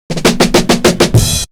Break 26.wav